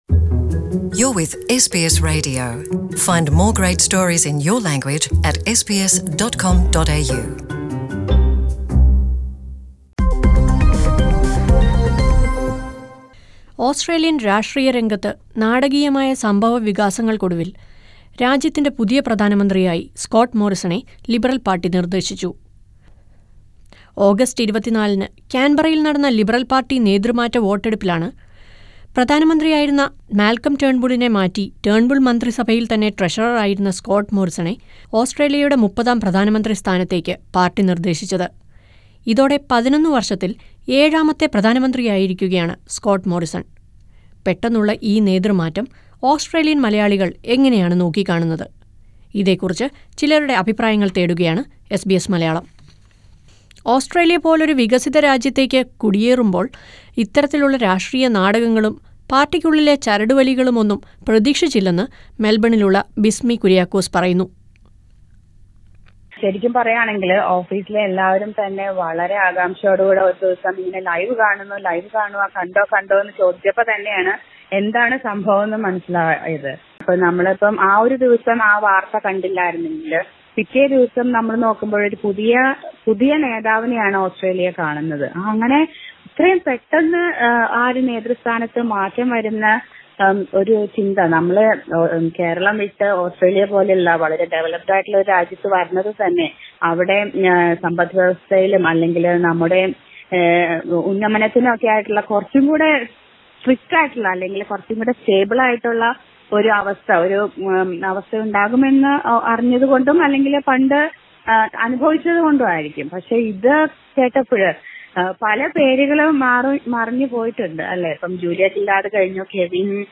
Listen to a few Australian Malayalees who share their opinions with SBS Malayalam.